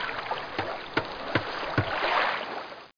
1 channel
shipyard2.mp3